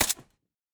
sounds / weapons / _bolt / 556_1.ogg